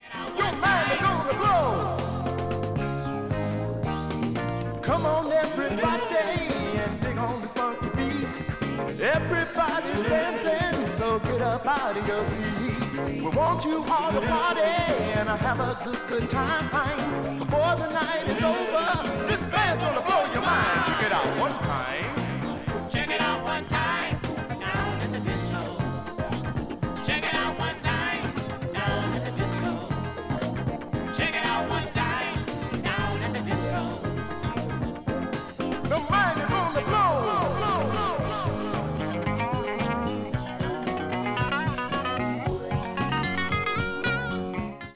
Funk / Soul